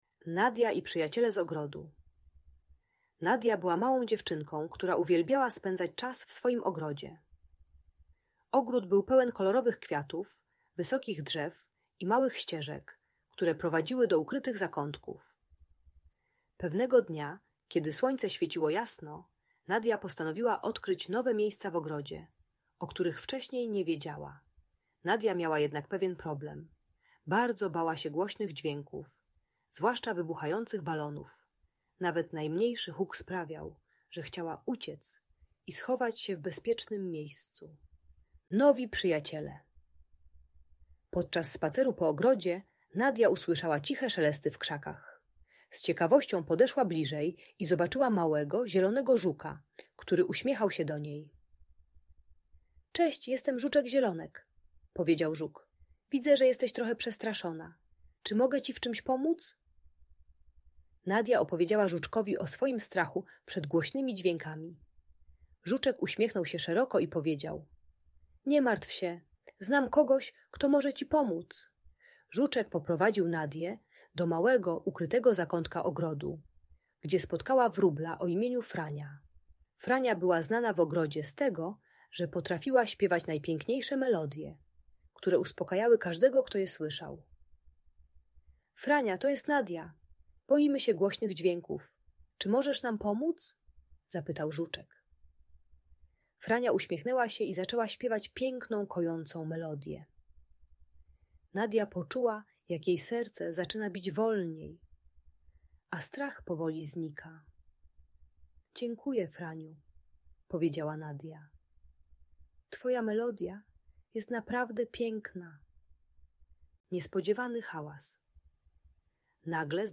Ta audiobajka o lęku i wycofaniu pomaga maluchowi przezwyciężyć strach przed hałasem (np. pękającymi balonami). Uczy techniki zadawania pytań o źródło dźwięku zamiast ucieczki oraz pokazuje siłę wsparcia przyjaciół.